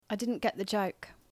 /j/ yacht versus / dʒ / jot